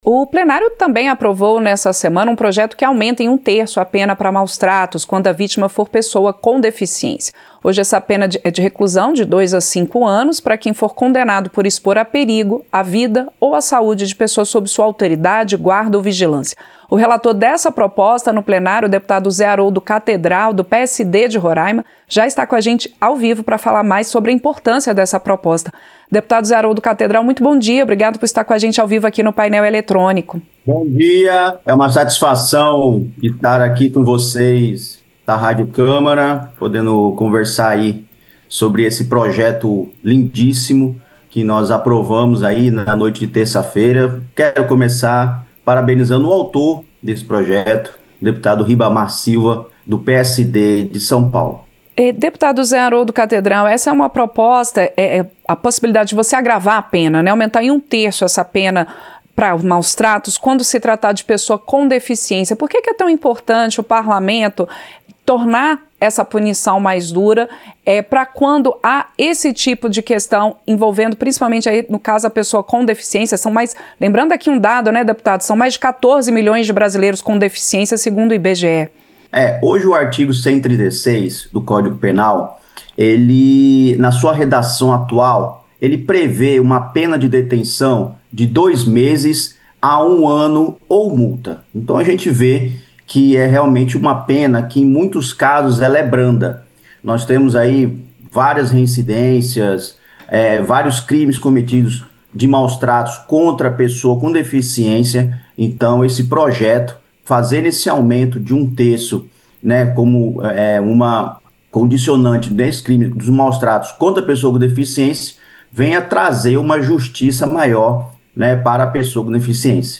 • Entrevista - Dep. Zé Haroldo Cathedral (PSD-RR)
Programa ao vivo com reportagens, entrevistas sobre temas relacionados à Câmara dos Deputados, e o que vai ser destaque durante a semana.